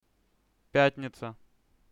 שישיPiatniza